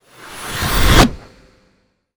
magic_conjure_charge2_02.wav